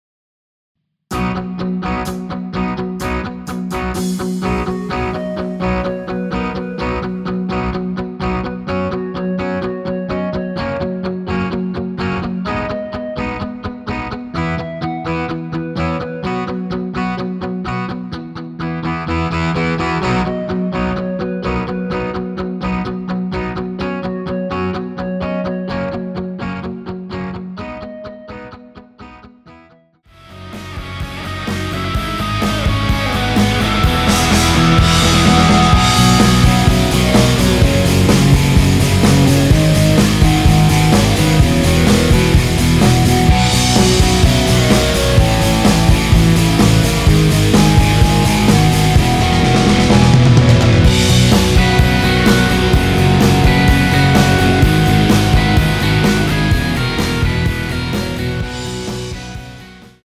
전주없이 노래 시작 하는 곡이라 전주 만들어 놓았습니다.
(하이 햇 소리 끝나고 노래 시작 하시면 됩니다.)
원키 멜로디 포함된 MR입니다.
앞부분30초, 뒷부분30초씩 편집해서 올려 드리고 있습니다.